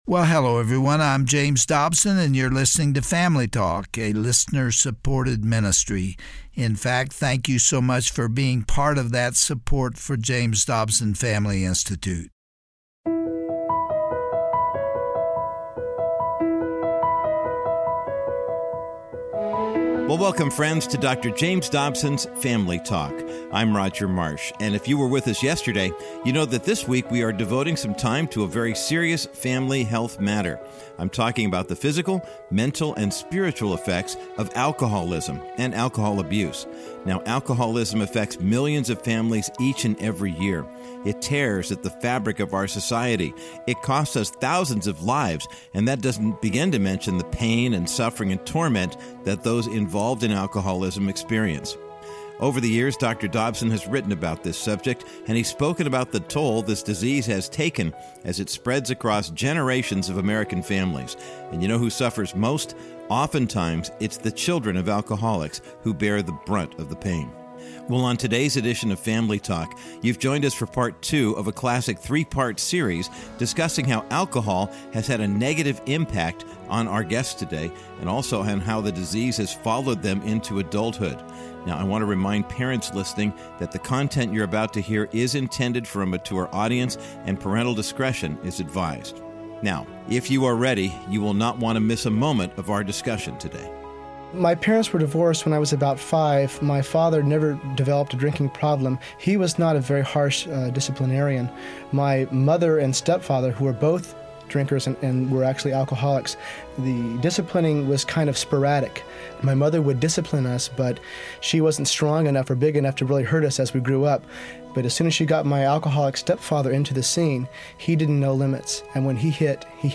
On today’s classic edition of Family Talk, Dr. James Dobson continues his discussion with a panel of qualified guests. Be encouraged as you listen to how God helped each of them work through the memories of a mom or dad in the throes of addiction.